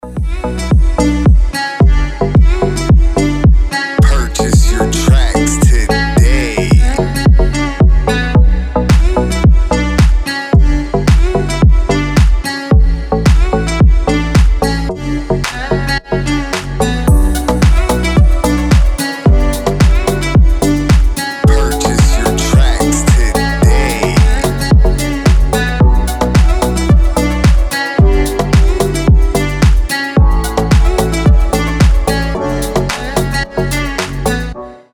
deep house
восточные мотивы